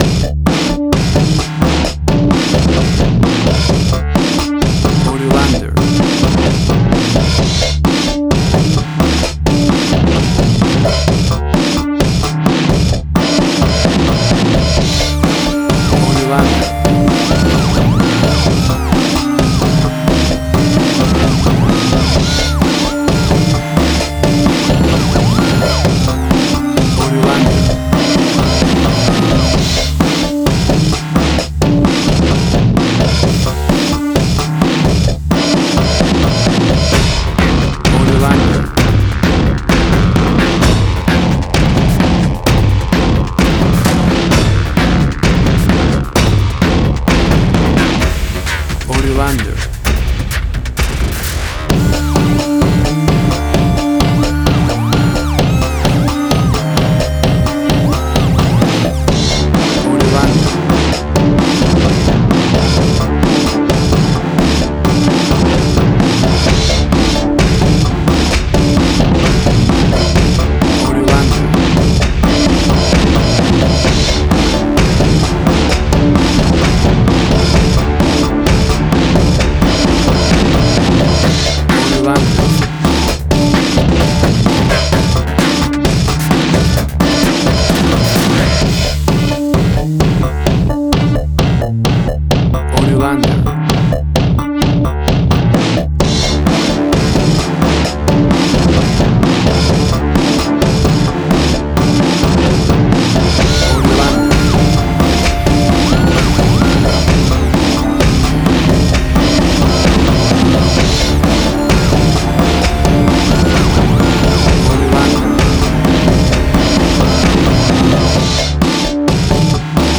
Suspense, Drama, Quirky, Emotional.
Tempo (BPM): 130